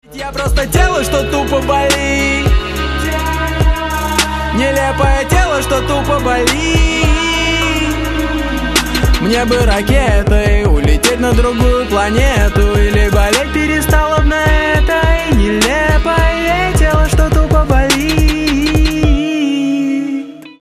мужской вокал
грустные
русский рэп
печальные
стук